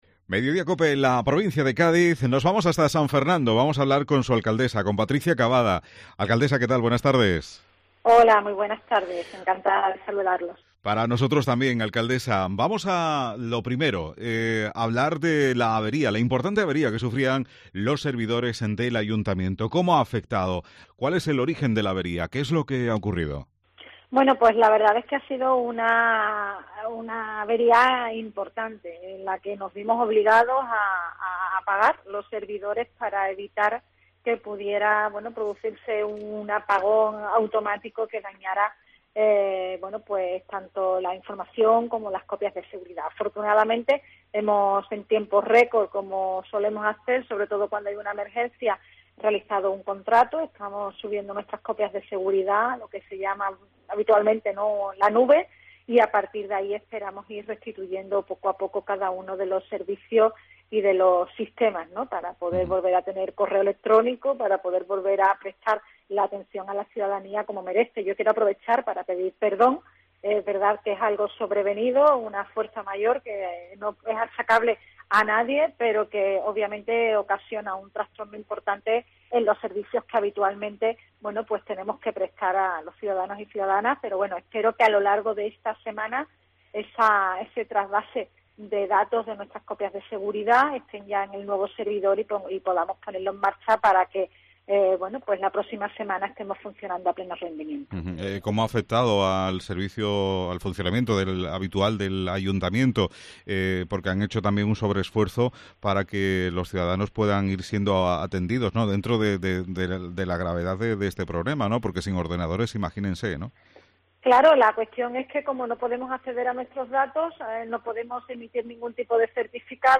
Patricia Cavada, alcaldesa de San Fernando, en COPE